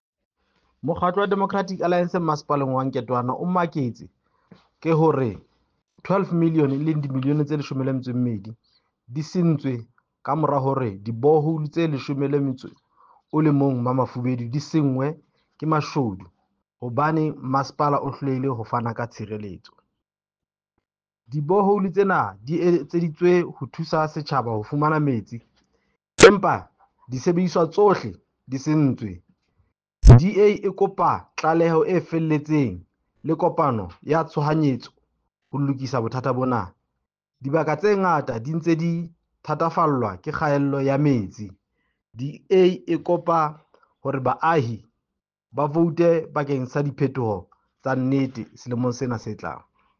Sesotho soundbites by Cllr Diphapang Mofokeng and Afrikaans soundbite by Cllr Anelia Smit.